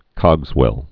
(kŏgzwĕl, -wəl)